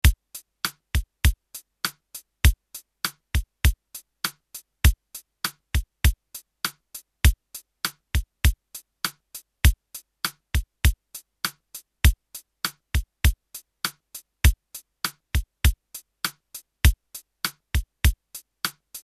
Denne slagverk-rytmen kalles ofte POP/ROCK.
bdr_sdr_cym.mp3